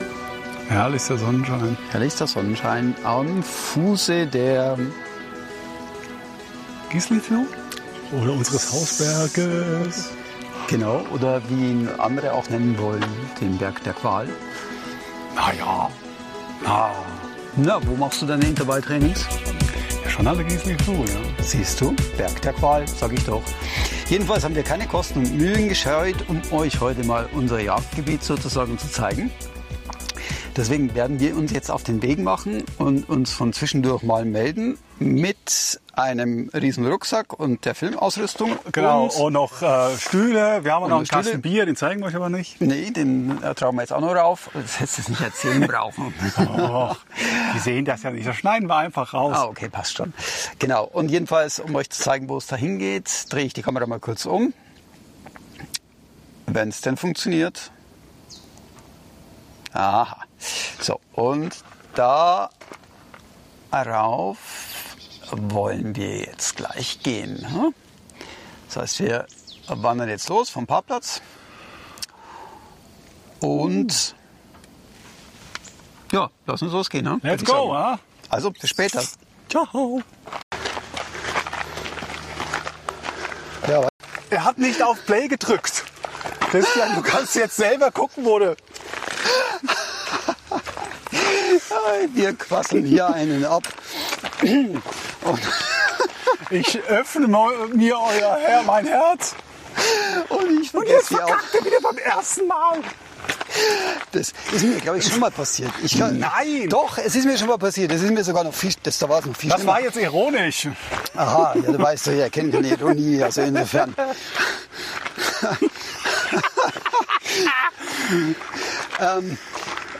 In Episode S2E7 melden wir uns live von der Gisiflue zurück aus der Sommerpause.